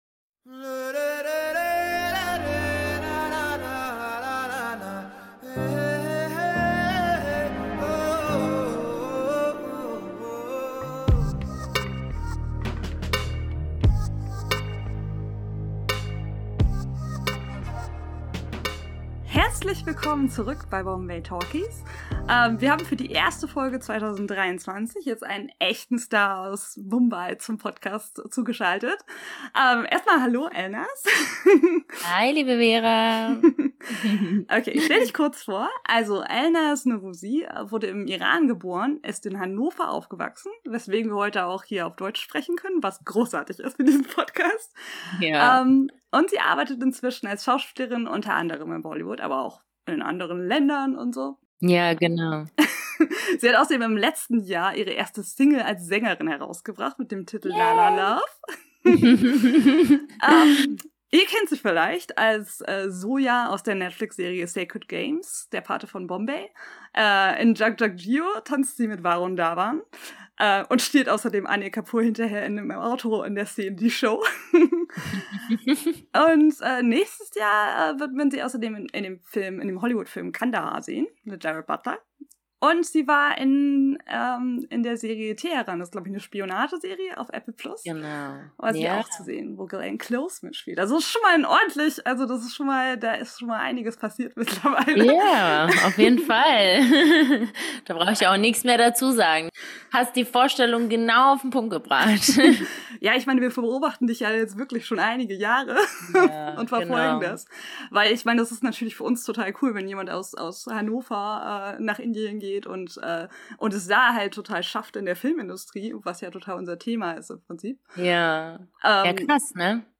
In der ersten Folge 2023 sprechen wir mit einem echten Star aus Mumbai! Elnaaz Norouzi erzählt uns, wie es wirklich ist, in der Filmindustrie zu arbeiten.